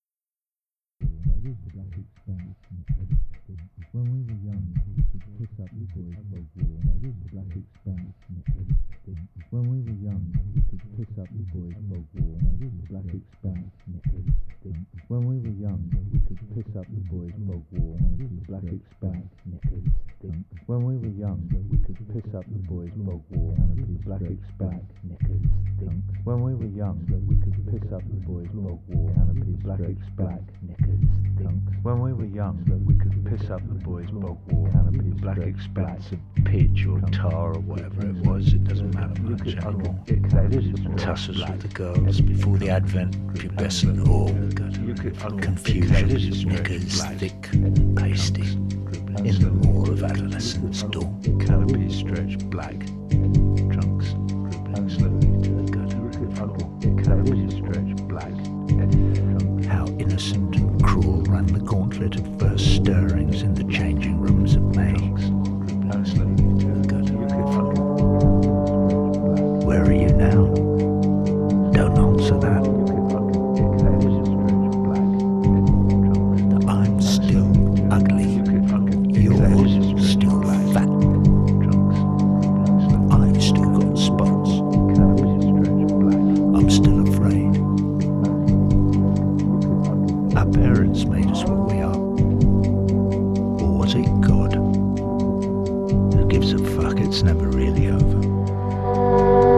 Progressive Rock, Rock